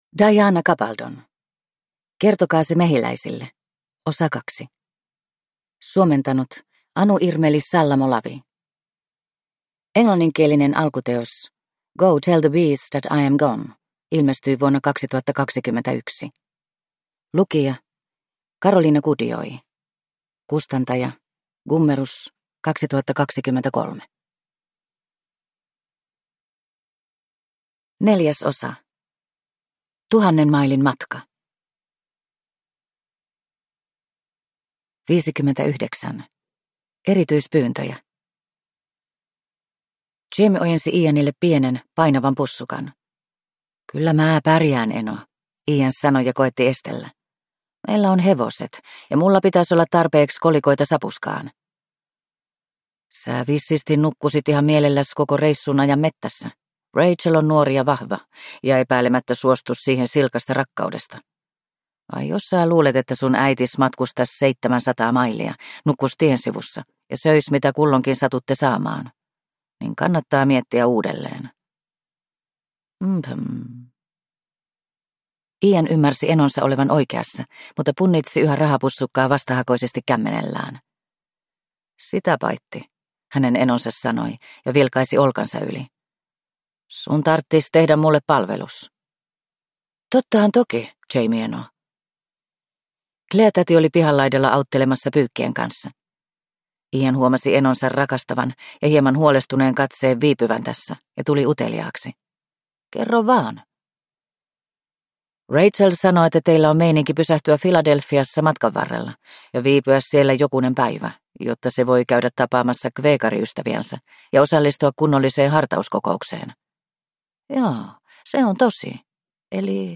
Kertokaa se mehiläisille, osa 2 – Ljudbok – Laddas ner
Uppläsare